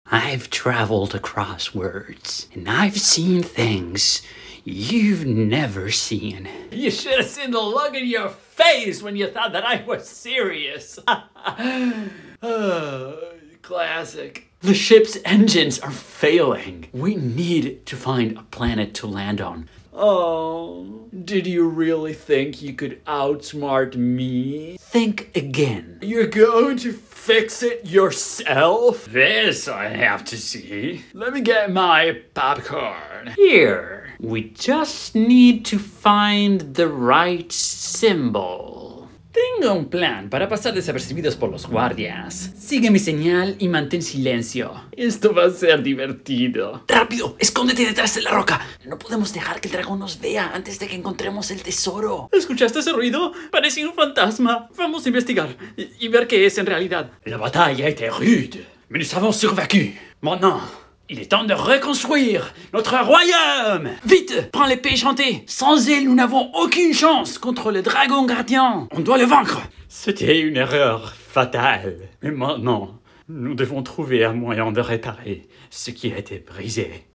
Voice reel
Voix 22 - 35 ans